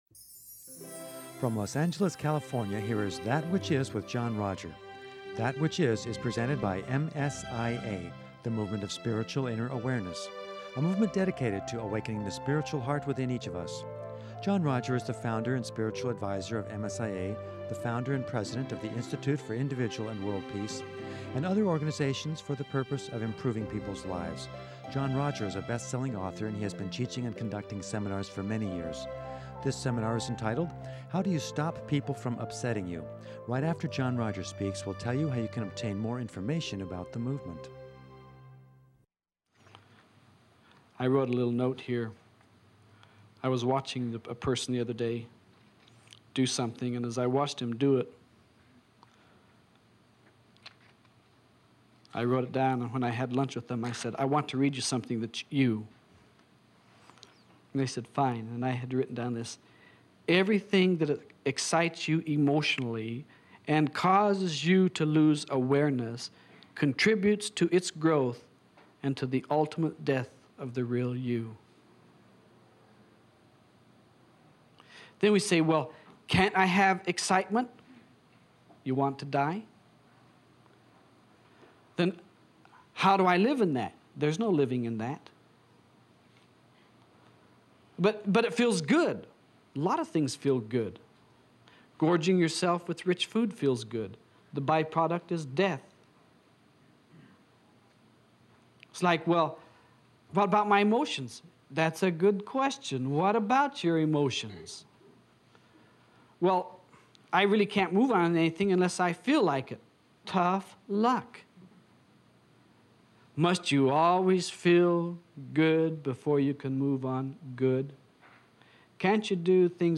The seminar closes with an explanation of how and why to make demands on Spirit.